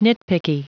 Prononciation du mot : nitpicky